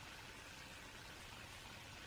zipline_sliding.wav